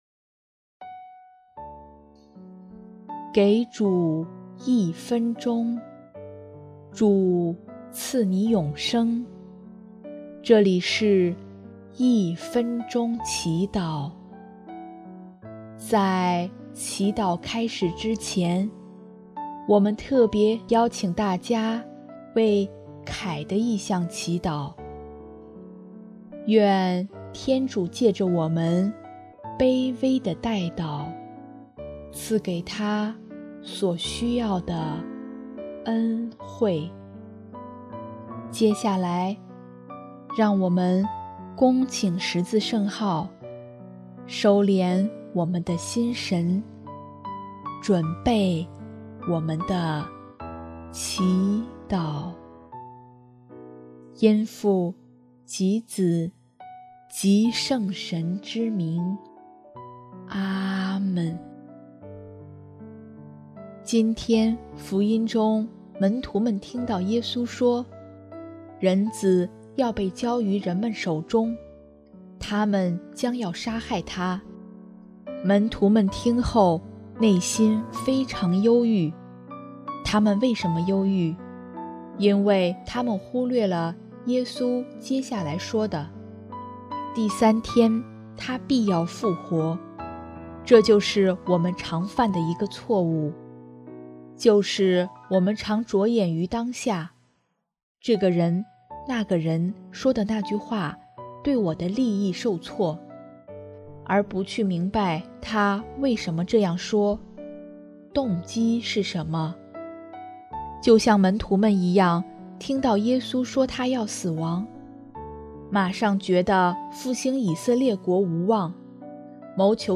音乐： 第三届华语圣歌大赛参赛歌曲优秀奖《爱的苏醒》